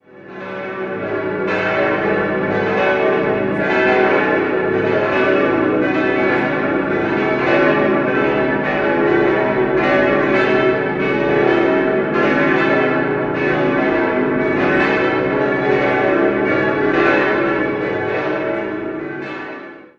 6-stimmiges Geläut: gis°-h°-cis'-e'-fis'-gis' Die Glocken 6, 5 und 3 wurden 1977/78 von der Firma Bachert in Bad Friedrichshall gegossen, die anderen drei 1947 bzw. 1955 von der Gießerei Hahn in Landshut bzw. Reichenhall.